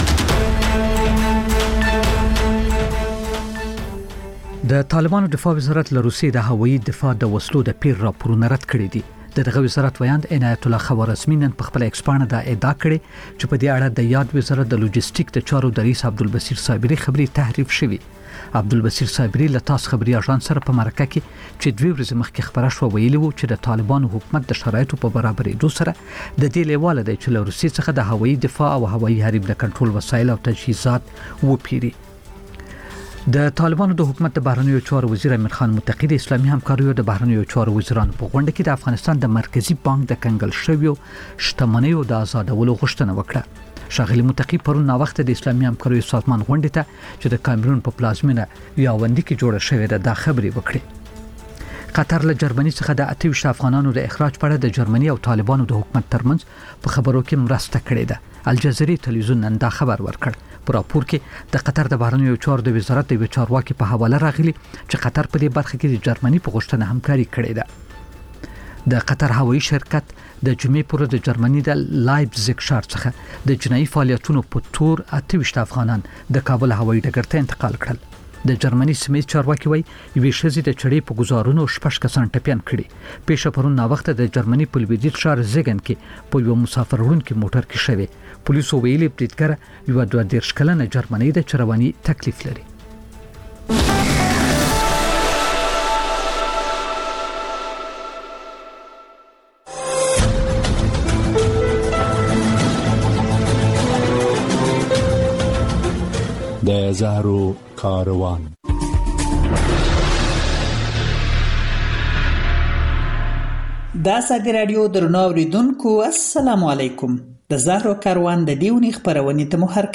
لنډ خبرونه - د زهرو کاروان (تکرار)